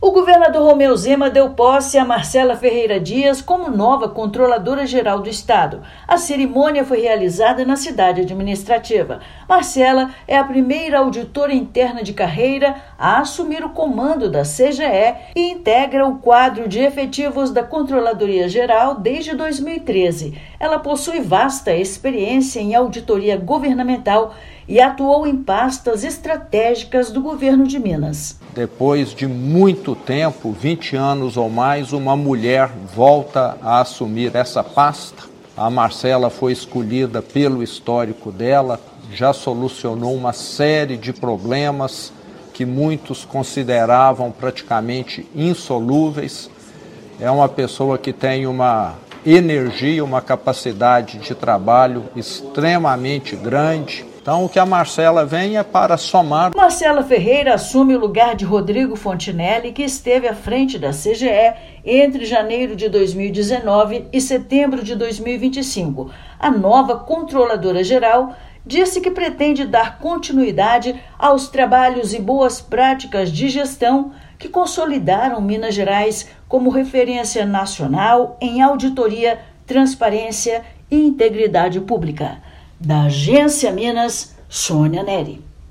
[RÁDIO] Governador de Minas empossa nova controladora-geral do Estado
Marcela Ferreira Dias é a primeira auditora interna de carreira à frente da Controladoria-Geral do Estado (CGE). Ouça matéria de rádio.